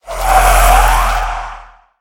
1.21.5 / assets / minecraft / sounds / mob / wither / idle1.ogg